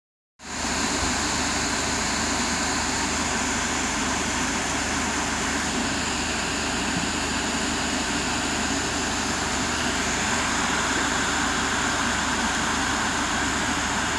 Сильный дождь в аэропорту